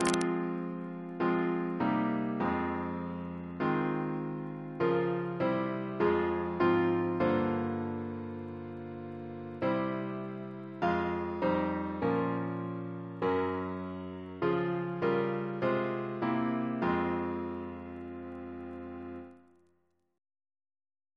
Double chant in F minor Composer